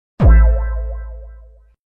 Rizz Sound Effect